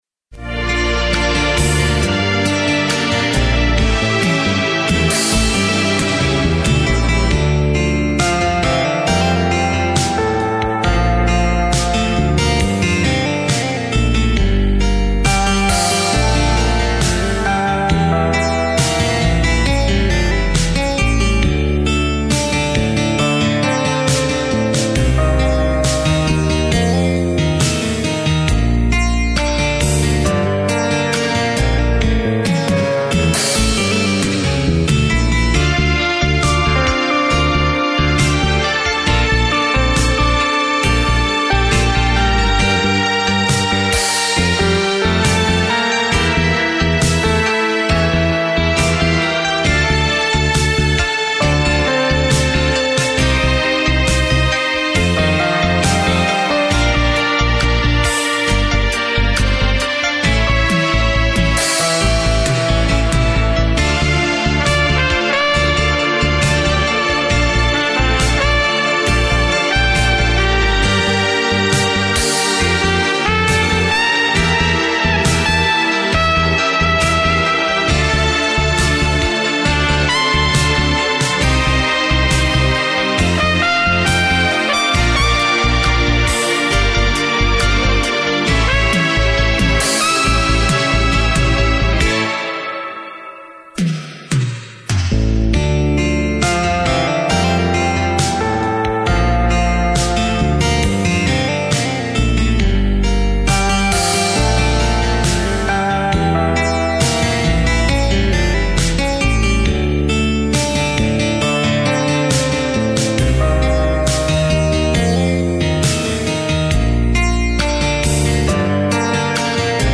• Жанр: Легкая